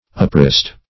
uprist - definition of uprist - synonyms, pronunciation, spelling from Free Dictionary
Search Result for " uprist" : The Collaborative International Dictionary of English v.0.48: Uprist \Up*rist"\ ([u^]p*r[i^]st"), n. Uprising.